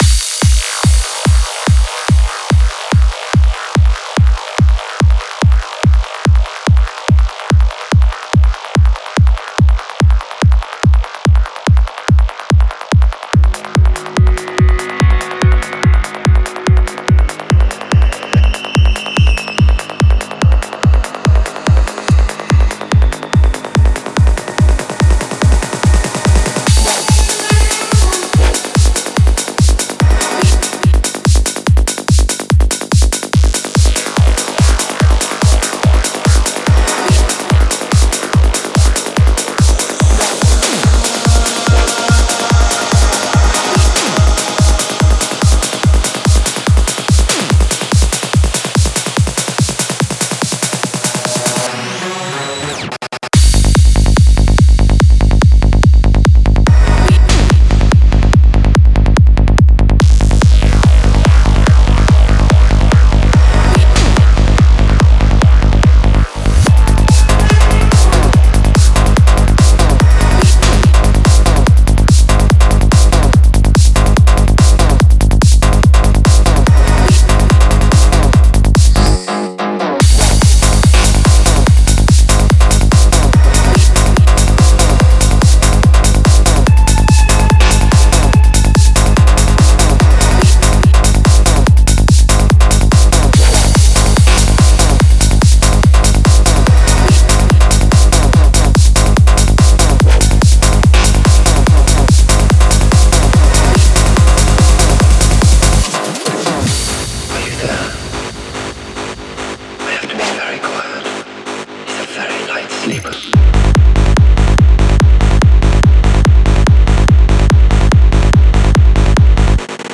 Файл в обменнике2 Myзыкa->Psy-trance, Full-on
Стиль: Trance / Psy Trance